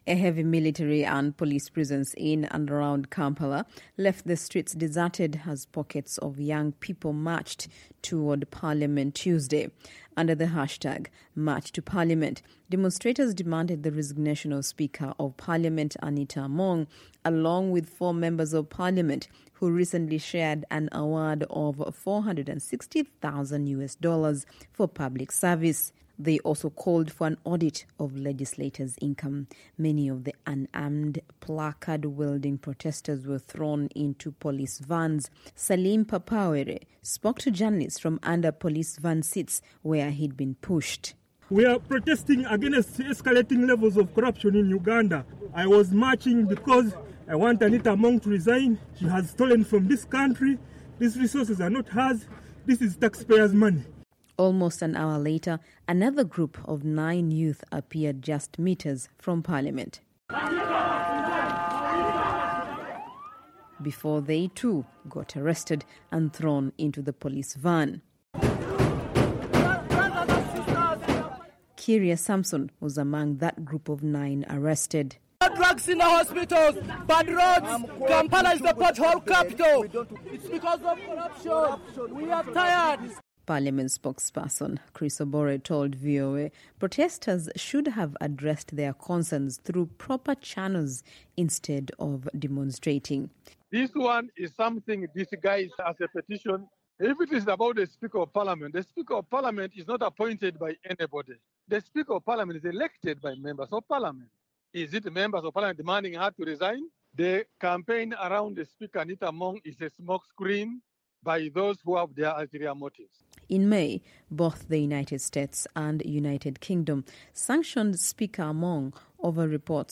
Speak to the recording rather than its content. reports from Kampala